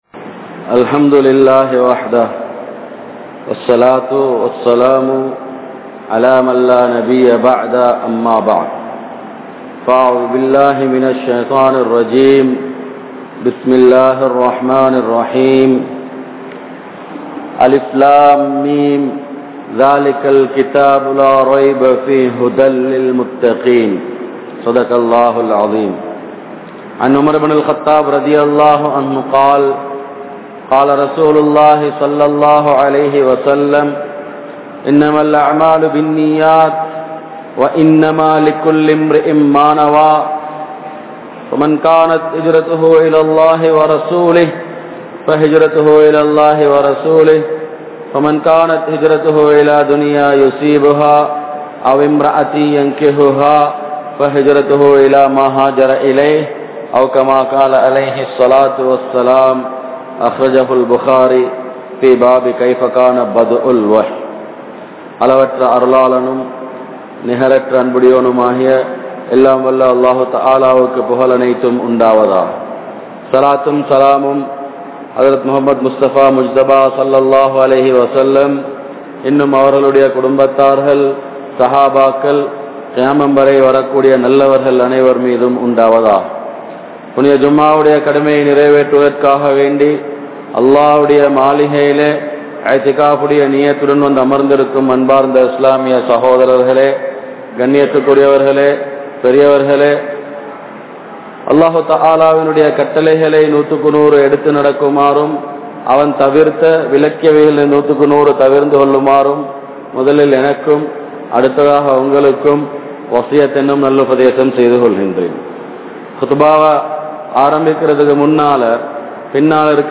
Allah`vukkaaha Paavaththai Vidum Vaalifarhal (அல்லாஹ்வுக்காக பாவத்தை விடும் வாலிபர்கள்) | Audio Bayans | All Ceylon Muslim Youth Community | Addalaichenai